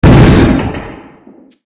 Download Cannon sound effect for free.
Cannon